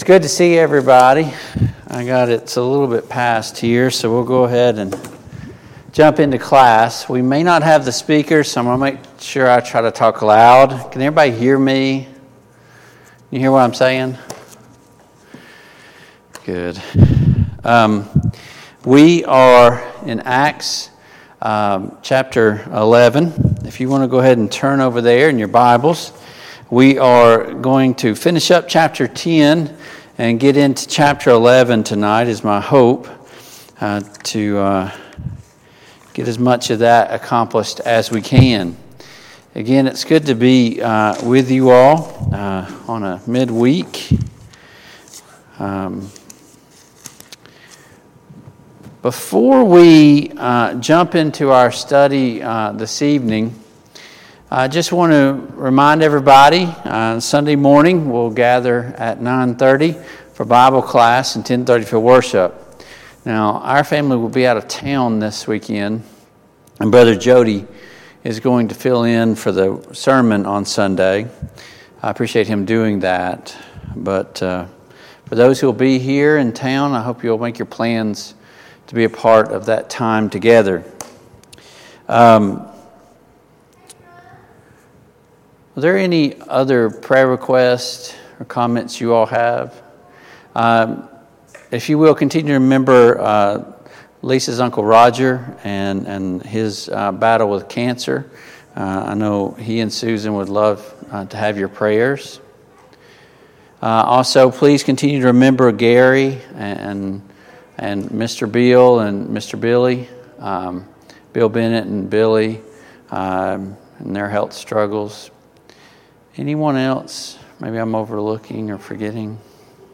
Mid-Week Bible Study